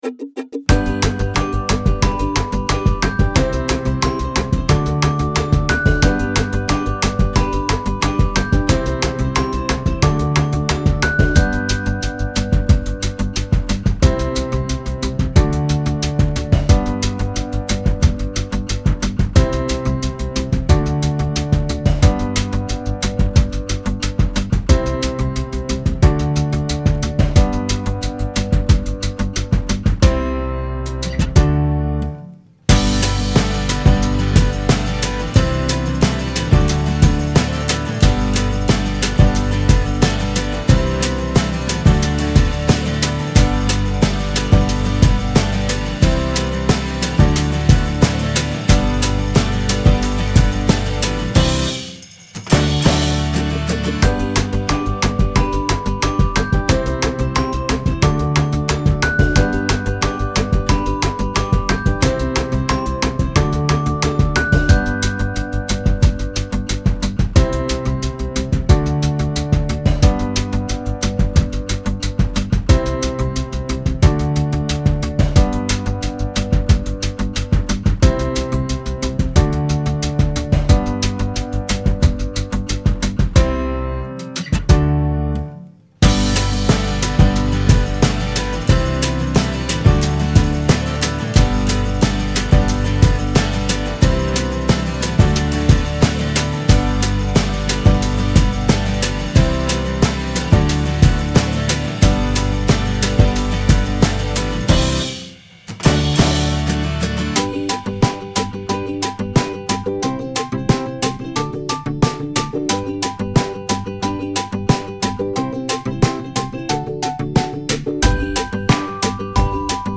Adolf-Clarenbach-Schule---Gemeinsam-sind-wir-stark-(PLAYBACK).mp3